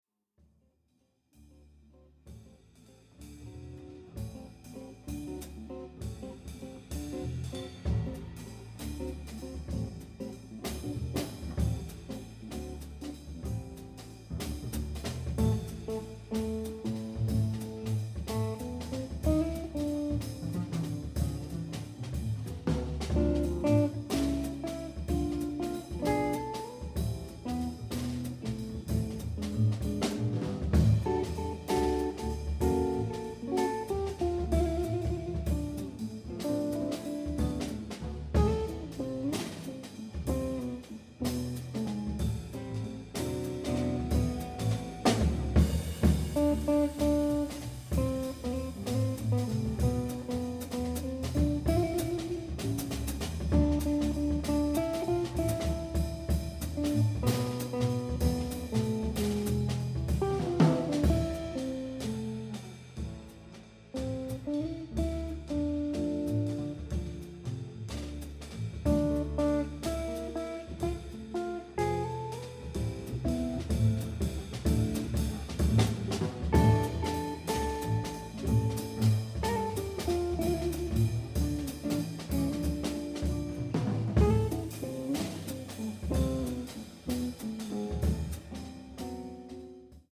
Guitar, Bass & Drums